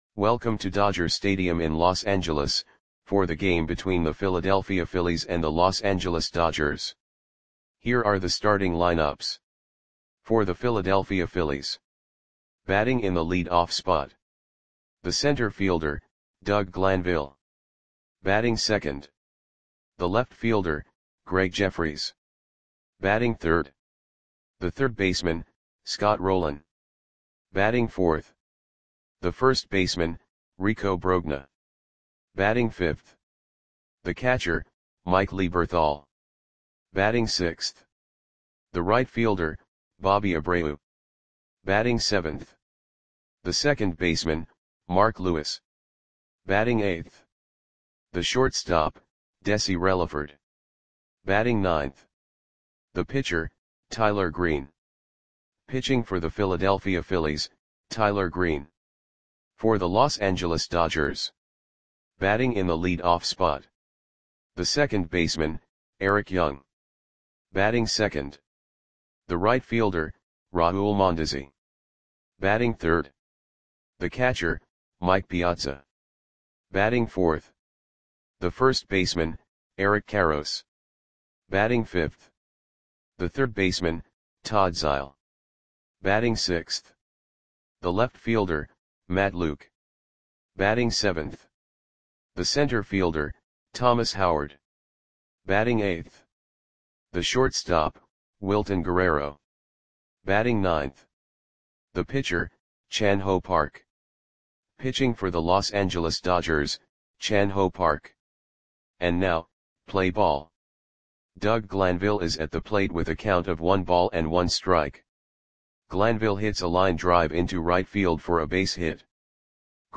Lineups for the Los Angeles Dodgers versus Philadelphia Phillies baseball game on May 13, 1998 at Dodger Stadium (Los Angeles, CA).
Click the button below to listen to the audio play-by-play.